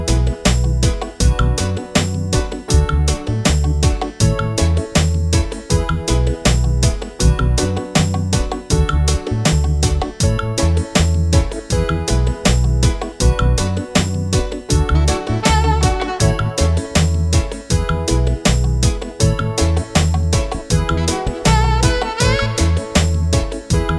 no Backing Vocals Reggae 3:52 Buy £1.50